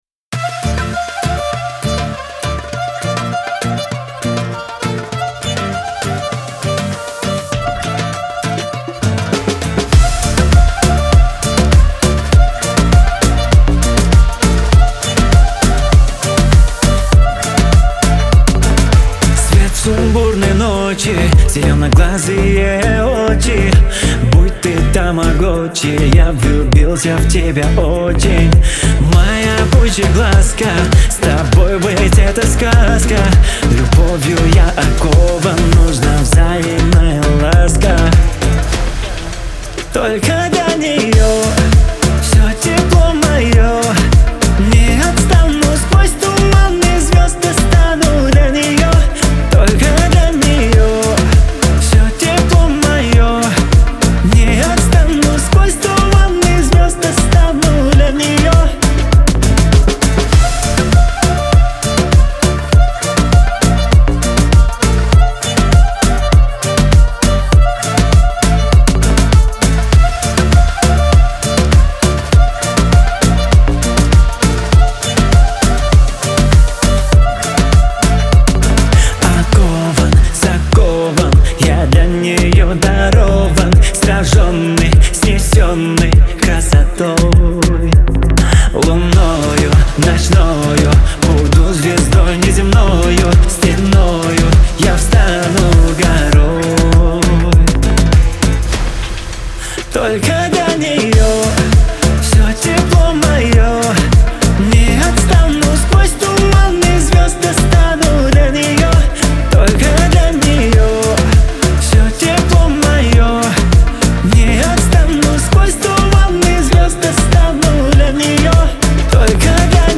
диско , pop